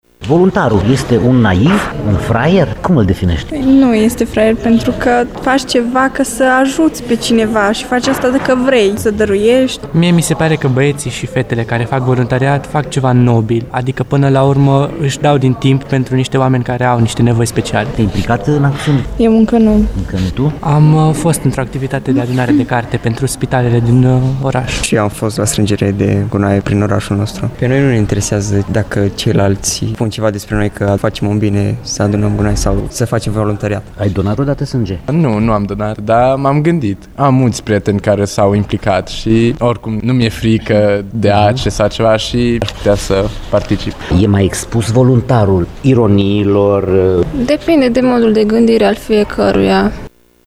Este una dintre concluziile trase la work shop-ul organizat astăzi, cu prilejul Zilei internaționale a voluntariatului, celebrată astăzi în Aula magna a fostei Universități ”Petru Marior”, actualul UMFST Tg.Mureș.
Studenții implicați au dat de înțeles că sunt obișnuiți cu ideea de voluntariat, că a fi voluntar nu e o jenă, chiar dacă, uneori, acesta poate deveni ținta ironiilor celorlalți: